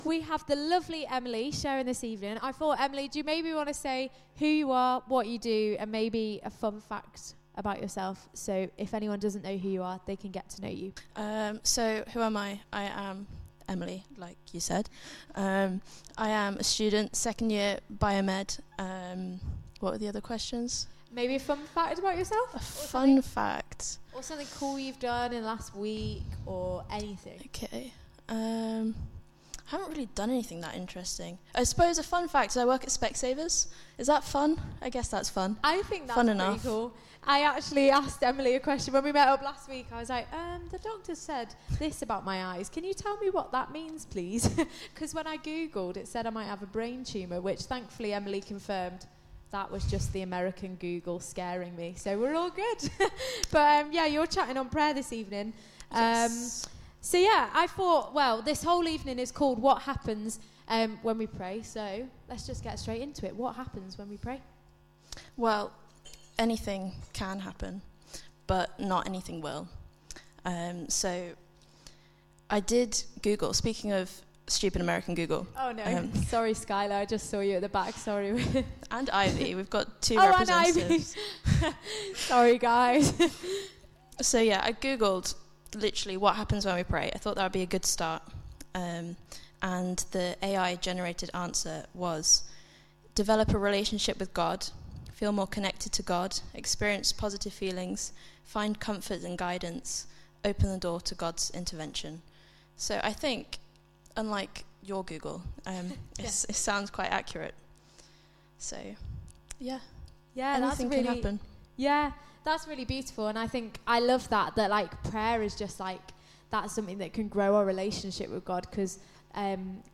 Recordings of our Sunday evening service Cafe Theology delivered as a podcast.